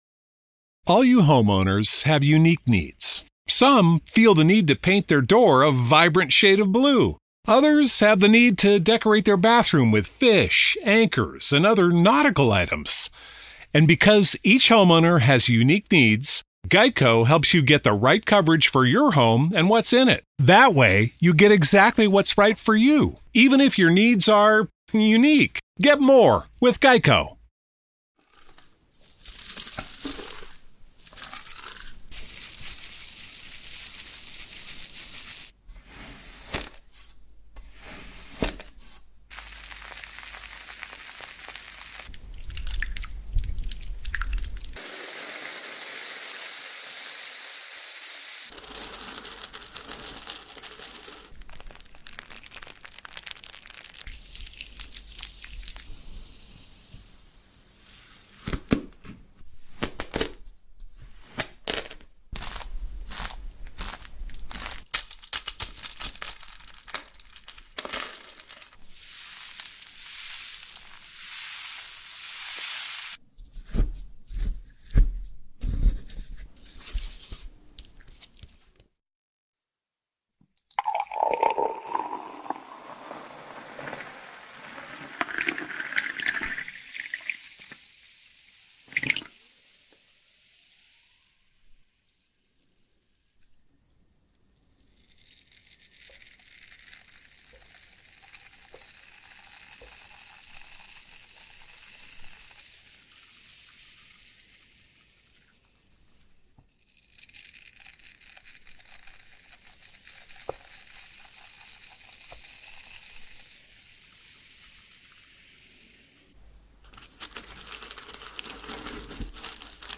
ASMR Sleep Intense Triggers to Give You Tingles ( No Talking)